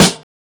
CardiakSnare 2.wav